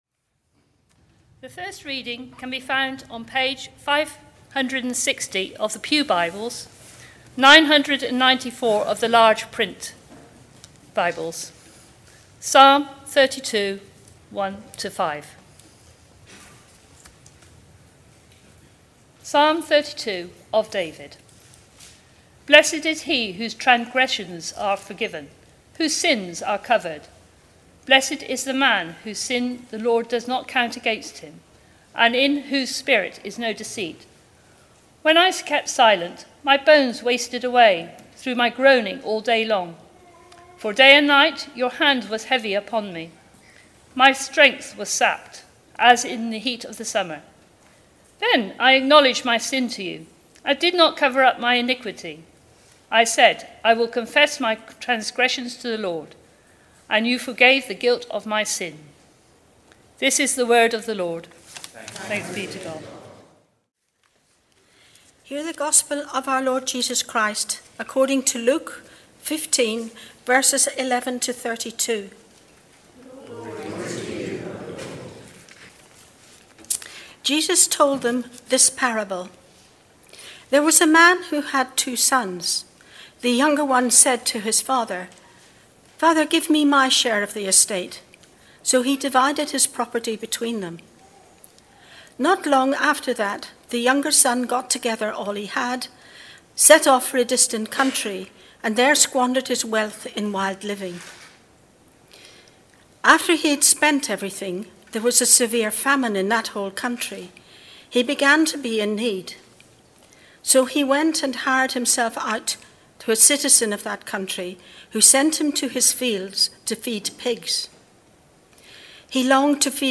Prodigious Sermon
Most sermons at St. Mary's are recorded and are available as computer files (.mp3) so that you can listen to them on your computer at home, or download them to transfer them to your portable music player (eg iPod).